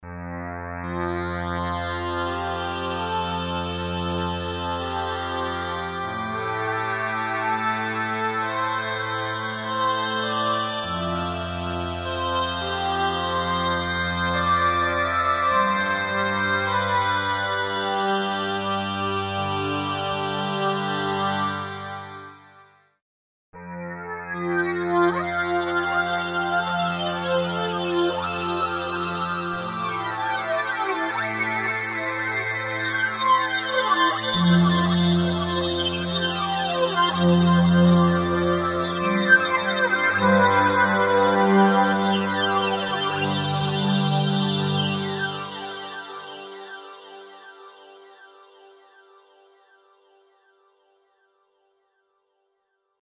Category: Sound FX   Right: Personal
Tags: Cakewalk - CamelSpace & Proteus soft Synth synthesizer CamelSpace Proteus Pack